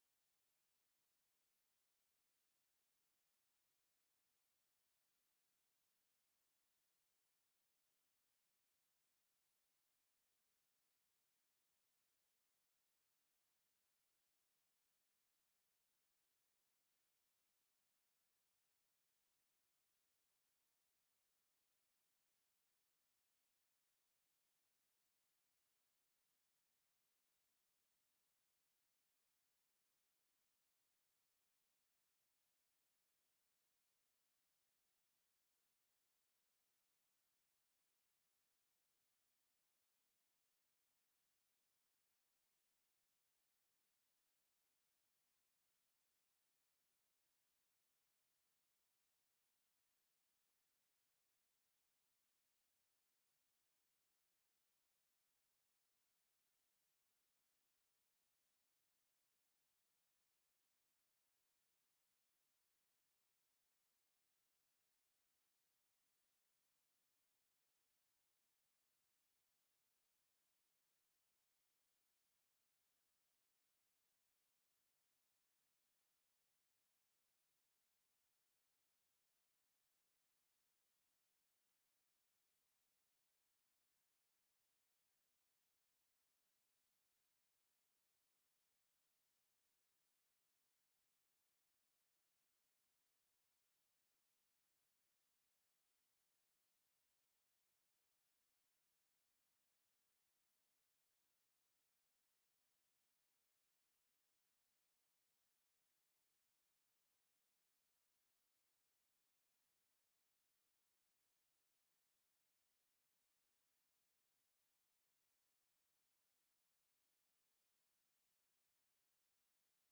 *Intrigerende muziek speelt*
*Luchtige piano-outro*